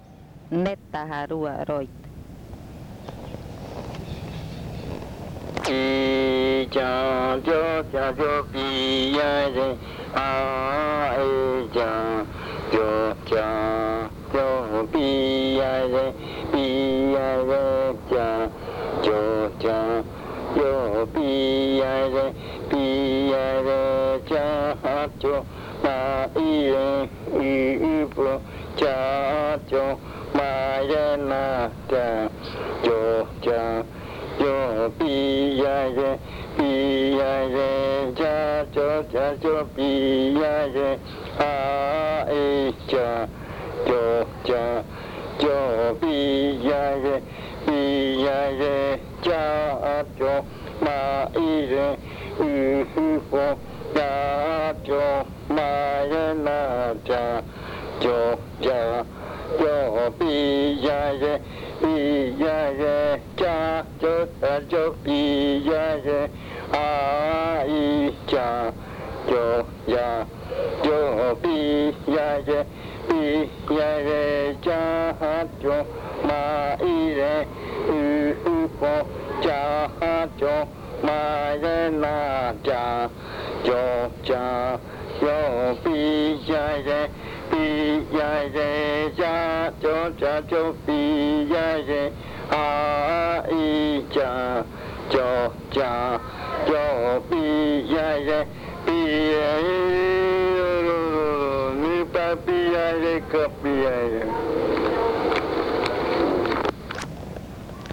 Leticia, Amazonas
Entry chant (netaja rua), 4:00 PM.
This chant is part of the collection of chants from the Yuakɨ Murui-Muina (fruit ritual) of the Murui people